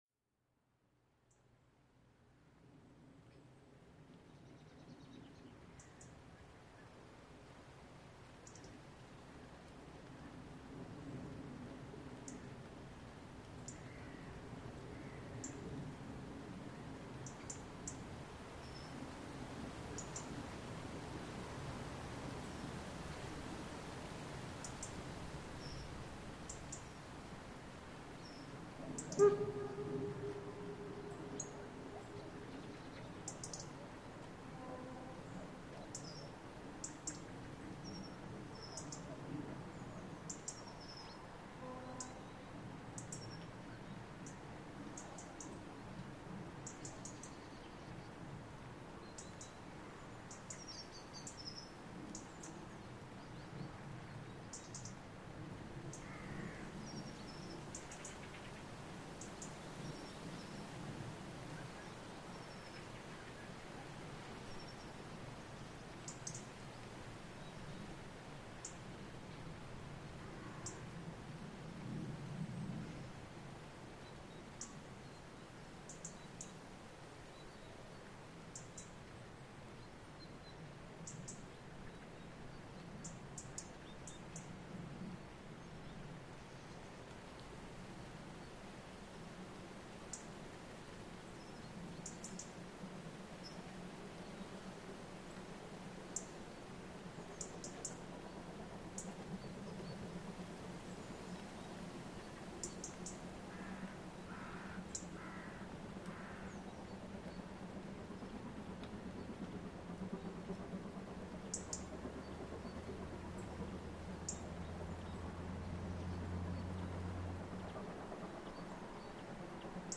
Aufgrund der Feier im Bahnhof Ochsenhausen anlässlich des 125-Jahre-Jubiläums waren an diesem Sonntag die Züge für die 788 besonders lang und sehr voll, damit gab es ordentlich viel Last, so dass die große und kräftige Lok richtig tolle und schön laute Bergfahrten präsentierte:
99 788 Tv mit langem Zug von Warthausen nach Ochsenhausen (anlässlich Bahnhofsjubiläum in Ochsenhausen), bei Wennedach, um 11:18h am 14.09.2025. Man beachte auch das Tempo auf dieser 750mm-Bahn und den Schleuderer etwa 20 Meter vor dem Aufnahmegerät - herrlich...;-)   Hier anhören: